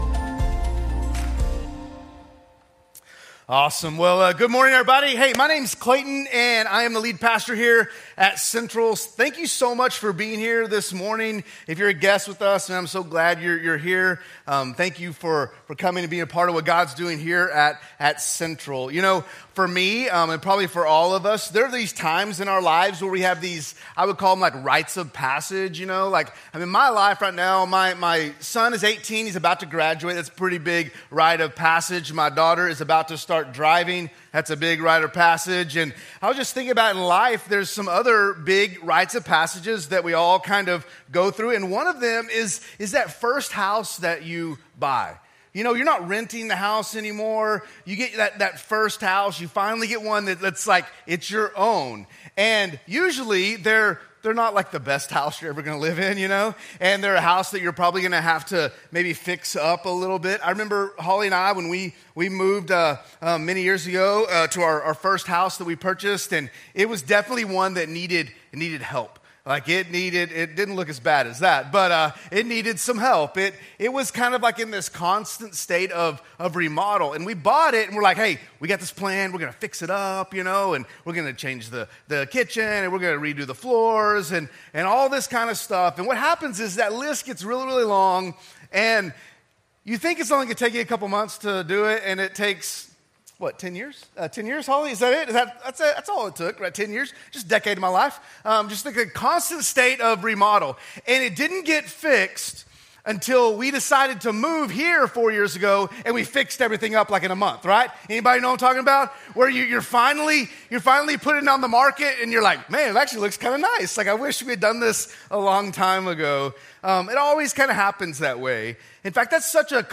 A message from the series "The Ostrich Syndrome."